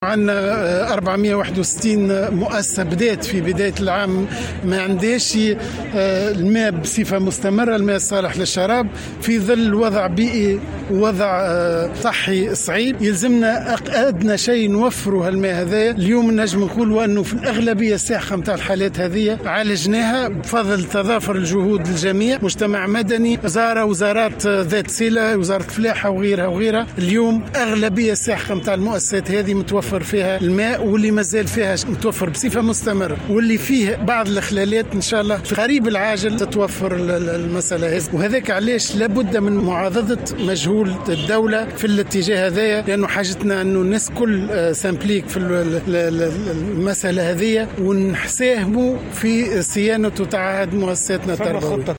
قال وزير التربية فتحي السلاوتي خلال اشرافه على انطلاق الحملة الوطنية لصيانة المدارس أن الوزارة لم تخصص اعتمادات اضافية لصيانة المدارس و إنما تعول على مساهمة المجتمع المدني والقطاع الخاص من أجل توفير مستلزمات صيانة المدارس.